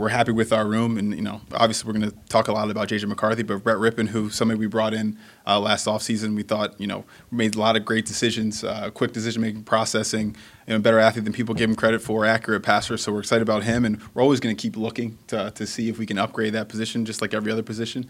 The Minnesota Vikings still aren’t ruling out the possibility of bringing in quarterback Aaron Rodgers. During a press conference yesterday, Vikes general manager Kwesi Adofo-Mensah said that the Vikings are happy with their current quarterback room, which is led by 2024 first-round pick J-J McCarthy.